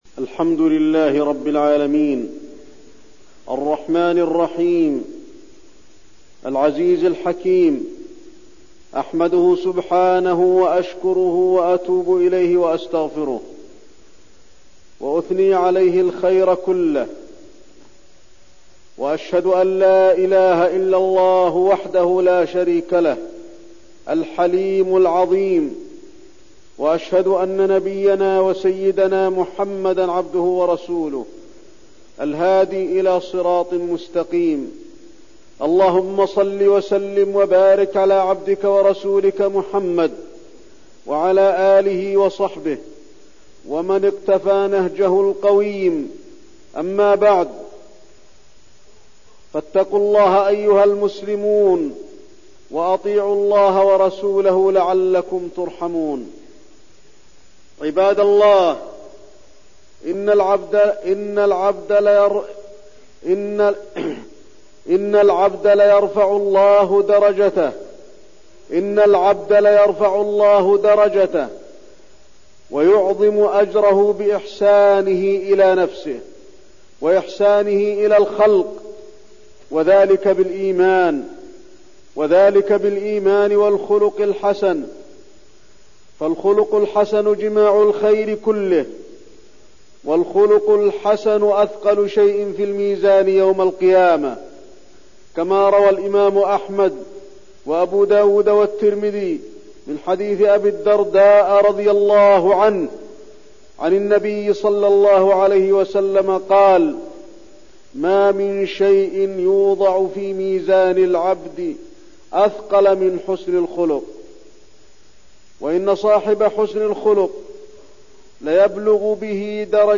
تاريخ النشر ٢٠ رجب ١٤١٢ هـ المكان: المسجد النبوي الشيخ: فضيلة الشيخ د. علي بن عبدالرحمن الحذيفي فضيلة الشيخ د. علي بن عبدالرحمن الحذيفي الخلق الحسن